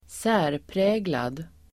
Ladda ner uttalet
särpräglad adjektiv, individual , distinctive Uttal: [²s'ä:rprä:lad] Böjningar: särpräglat, särpräglade Synonymer: karakteristisk, speciell, typisk, udda, utmärkande Definition: annorlunda, karakteristisk